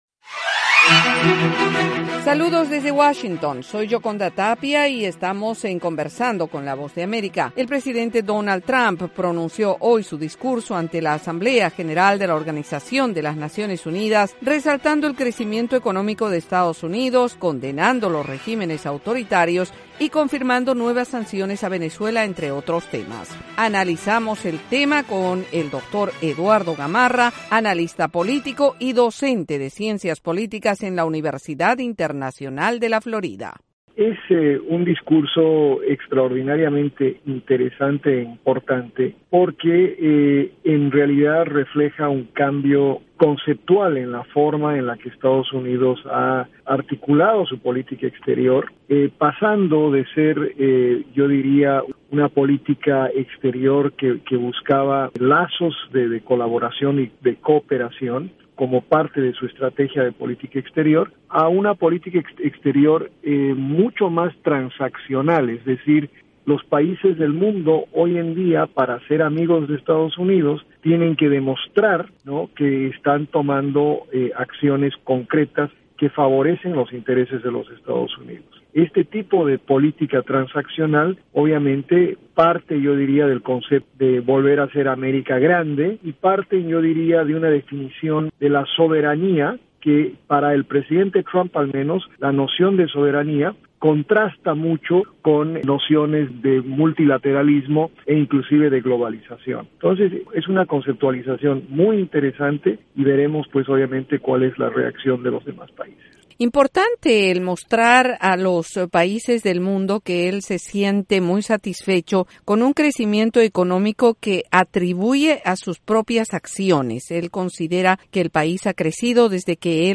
y en la entrevista el experto destaca los temas relacionados con el crecimiento económico de EE.UU., los régimenes autocráticos y las sanciones a personas del entorno cercano al presidente Nicolás Maduro.